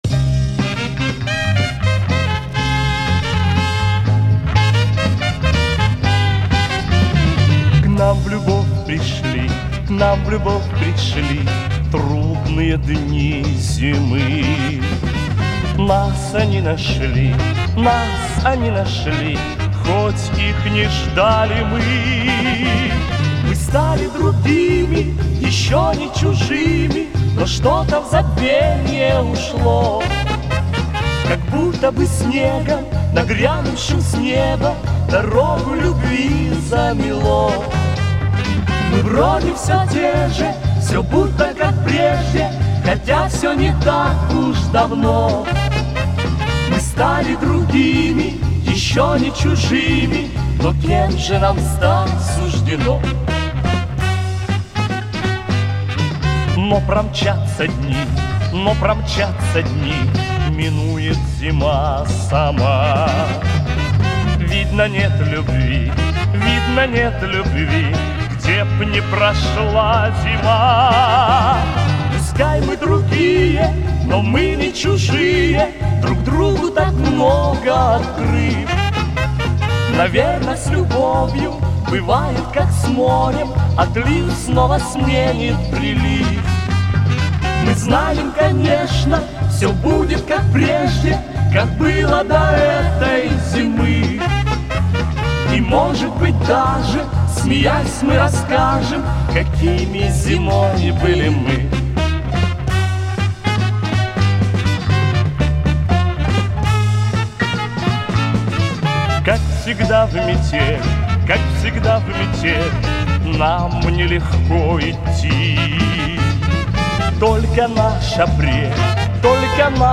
Не с интернета - сам оцифровывал с пластинки.